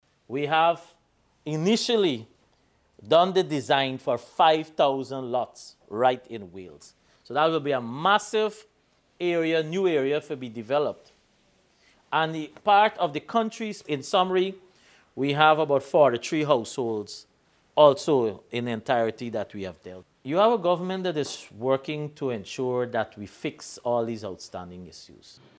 Minister Collin Croal revealed this development during a community meeting at Anna Catherina on the West Coast of Demerara.